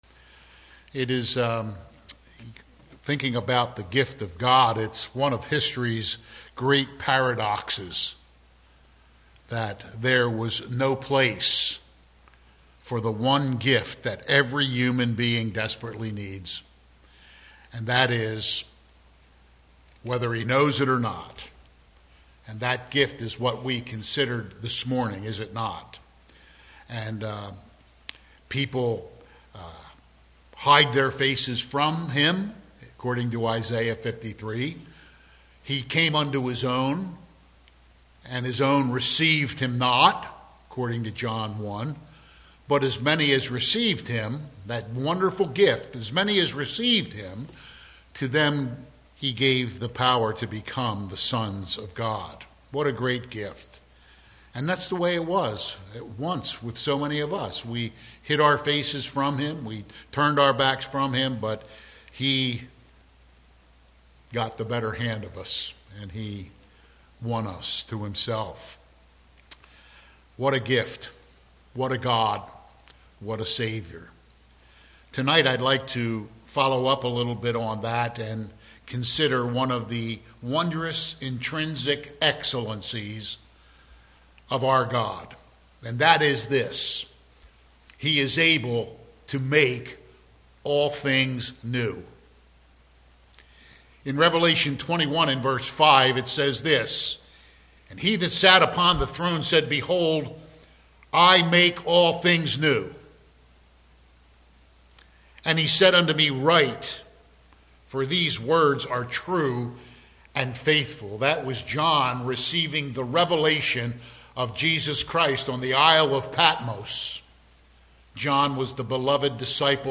Passage: Revelation 21:5 Service Type: Sunday PM